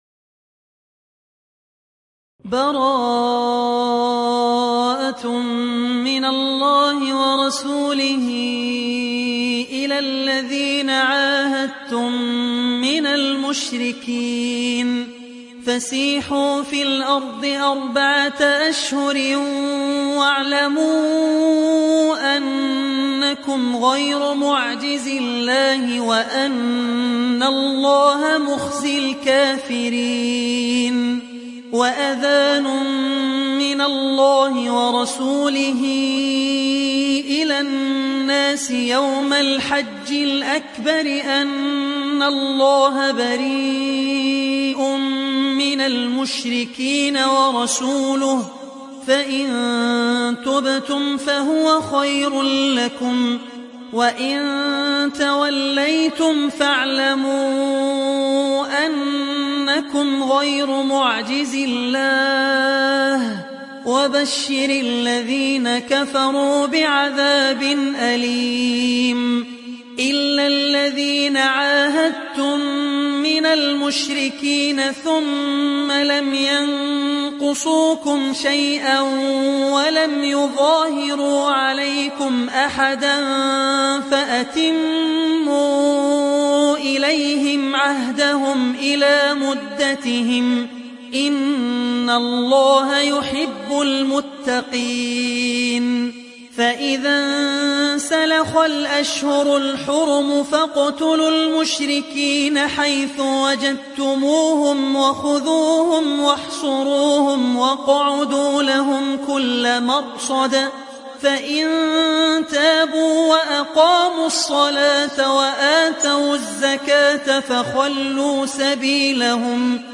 Riwayat Hafs from Asim